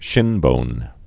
(shĭnbōn)